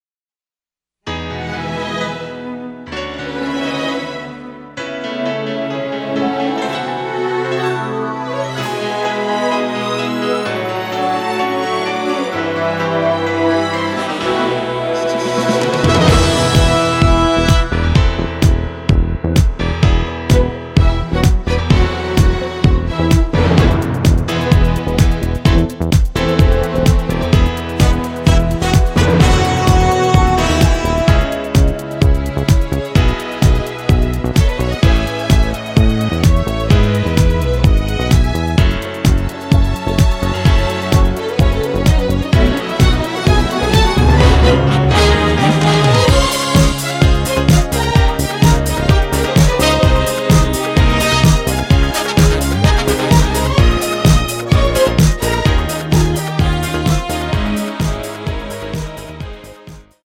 [공식 음원 MR]
앞부분30초, 뒷부분30초씩 편집해서 올려 드리고 있습니다.